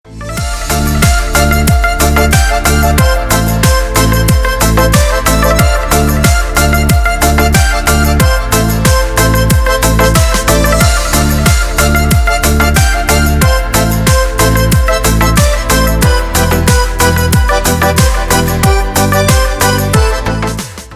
• Песня: Рингтон, нарезка
• Категория: Красивые мелодии и рингтоны